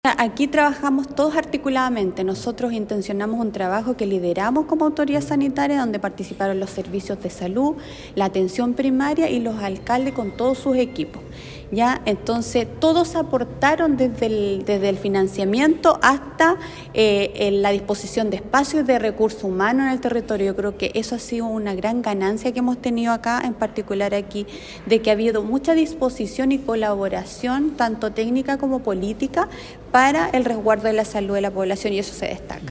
Por último, la Seremi de Salud, Karin Solís destacó el trabajo coordinado que se realizó con los Servicios de Salud y los distintos dispositivos de salud primaria municipal, que permitieron sortear de buena manera esta campaña de invierno.